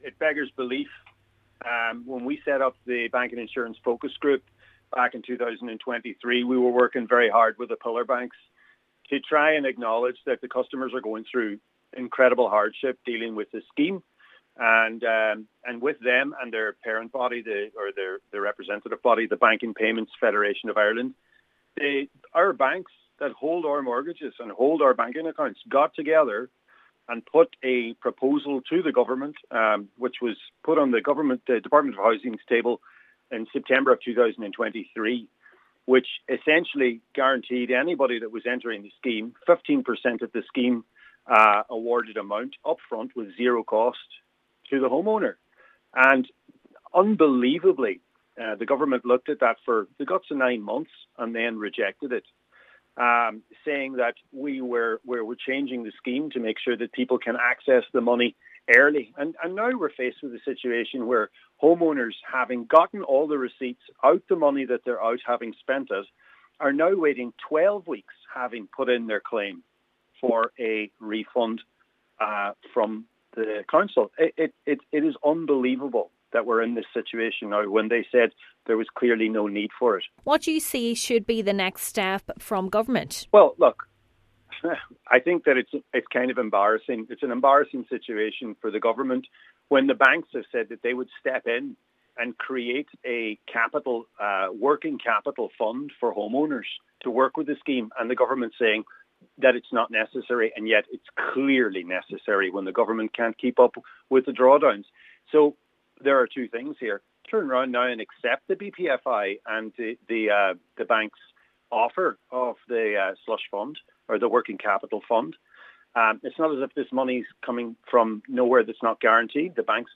Statement in full –